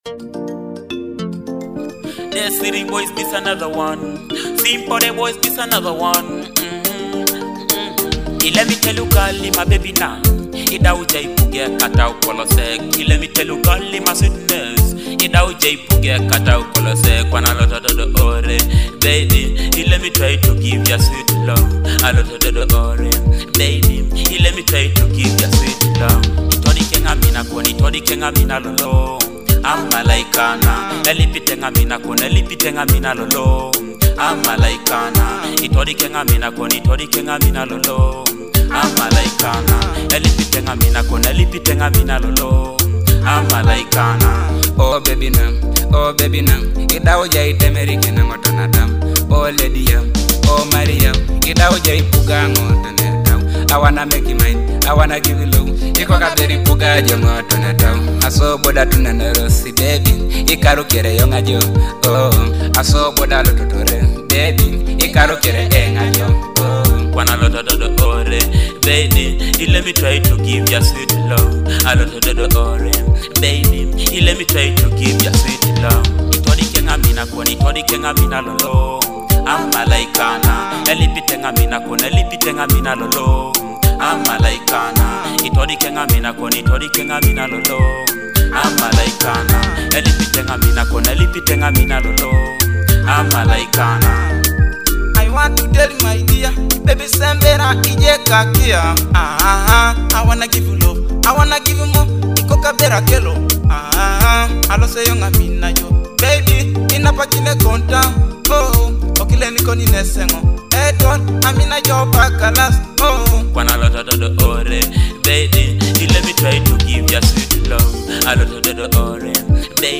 a vibrant Ateso dancehall hit
blending electrifying beats with Eastern Ugandan flavor.
a high-energy Ateso love hit MP3 track